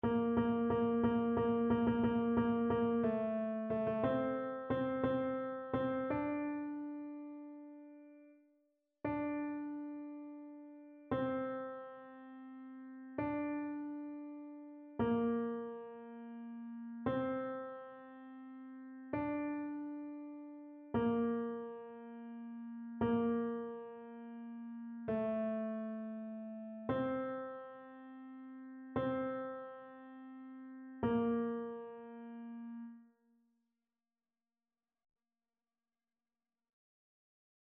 annee-b-temps-ordinaire-30e-dimanche-psaume-125-tenor.mp3